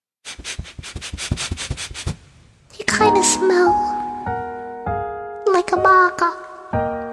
sniff-baka.mp3